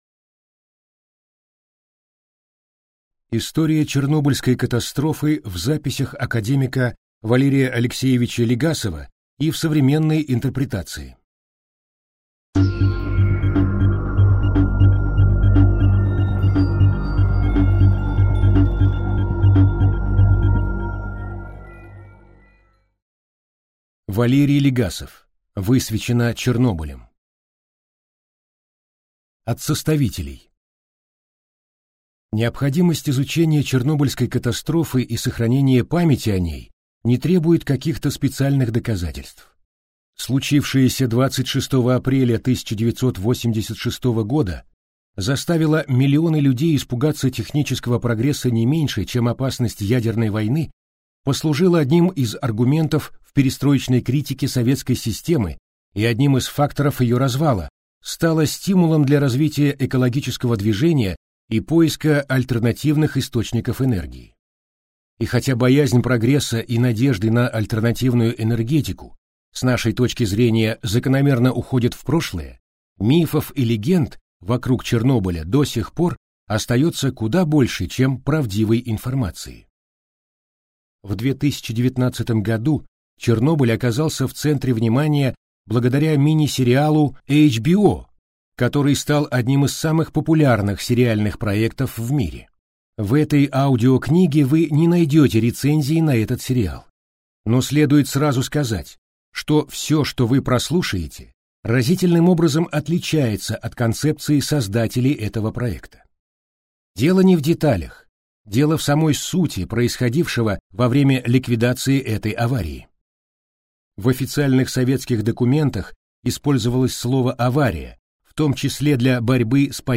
Аудиокнига Валерий Легасов: Высвечено Чернобылем | Библиотека аудиокниг